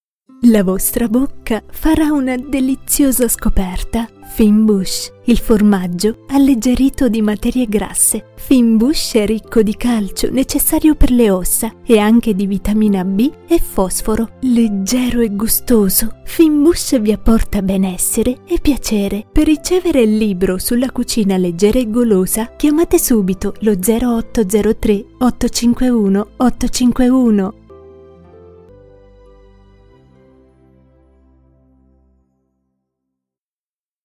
Voice Over Femminile Giovane / Tonica / Fresca Una voce sorridente, rassicurante, elegante, istituzionale, posata, convincente, sensuale, misteriosa, intima…
italienisch
Sprechprobe: Werbung (Muttersprache):
Voice Over - Female - Young / Dynamic / Fresh A happy voice, reassuring, elegant, institutional, calm, convincing, sensual, mysterious, intimate...